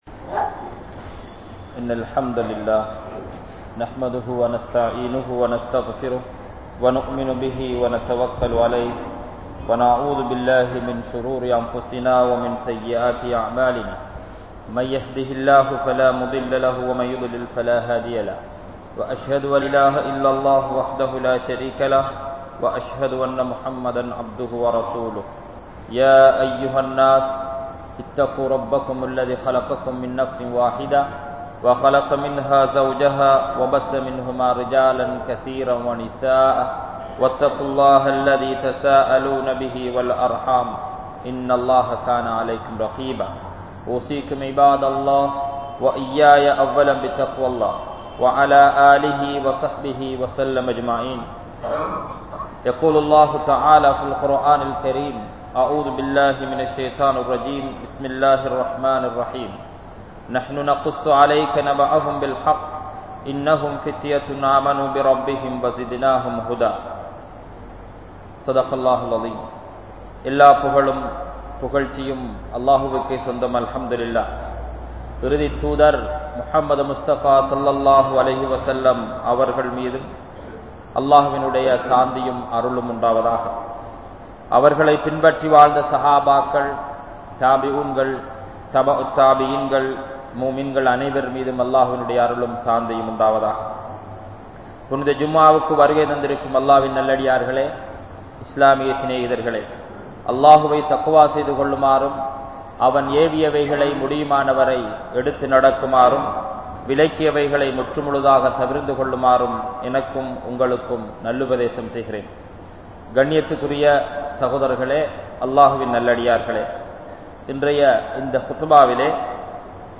Katpuhalai Ilakkum Indraya Vaalifarhalum Uvathihalum (கற்புகளை இழக்கும் இன்றைய வாலிபர்களும் யுவதிகளும்) | Audio Bayans | All Ceylon Muslim Youth Community | Addalaichenai
Grand Jumua Masjith